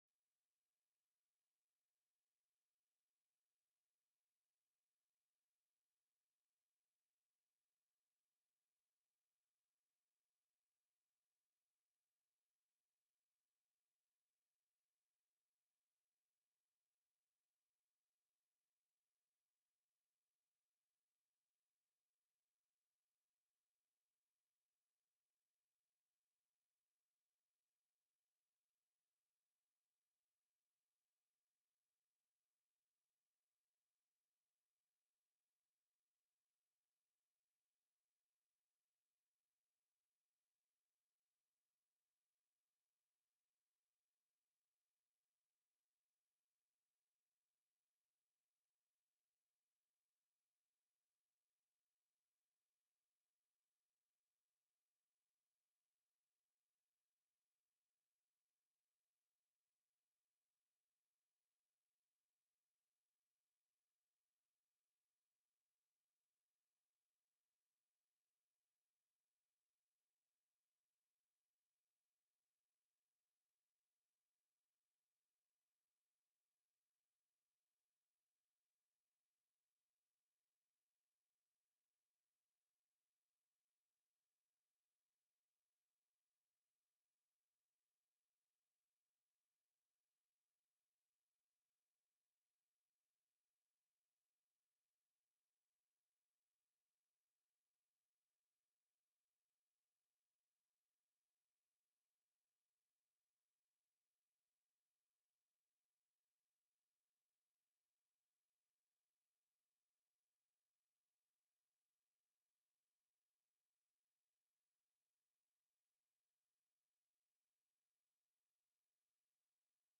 The audio recordings are captured by our records offices as the official record of the meeting and will have more accurate timestamps.
SB 274 PERMANENT FUND POMV AVAILABLE FOR APPROP TELECONFERENCED